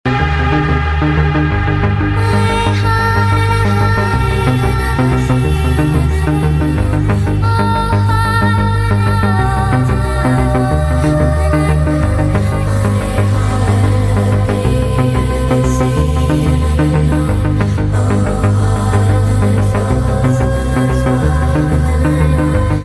The wuality is alrd horrendous enough tik tok will probably make it worse